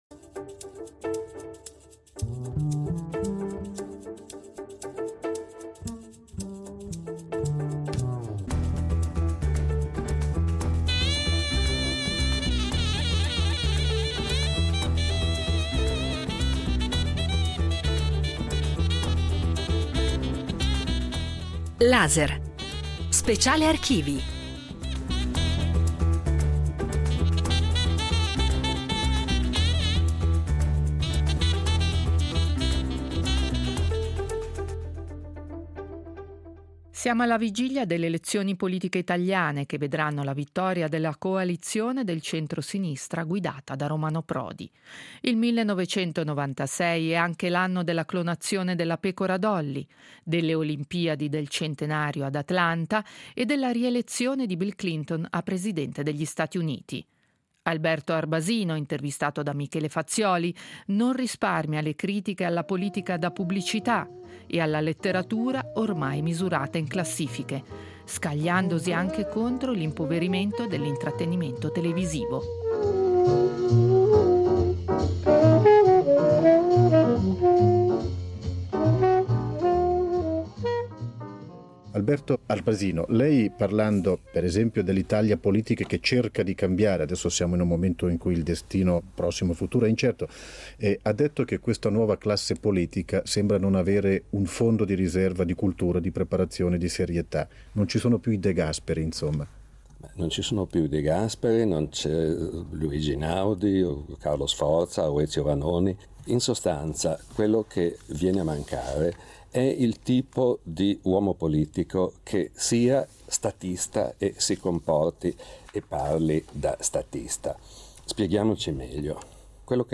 Intervista ad Alberto Arbasino in collaborazione con gli Archivi RSI